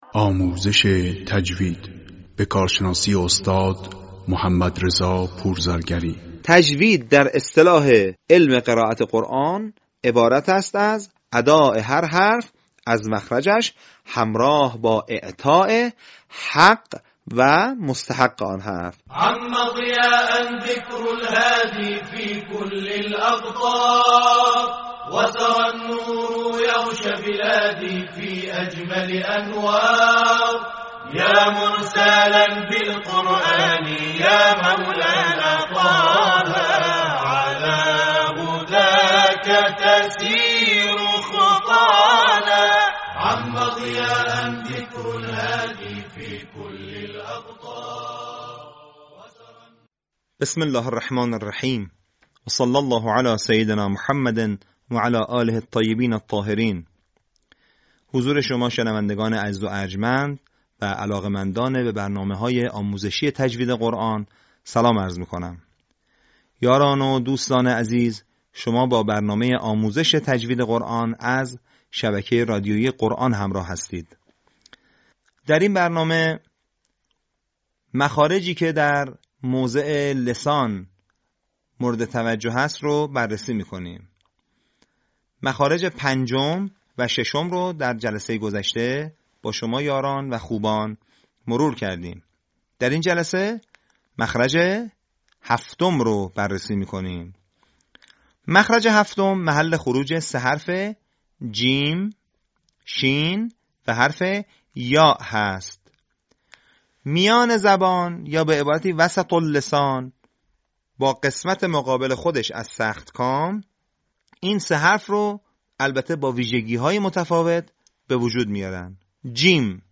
صوت | مخارج حروف در موضع لسان
به همین منظور مجموعه آموزشی شنیداری(صوتی) قرآنی را گردآوری و برای علاقه‌مندان بازنشر می‌کند.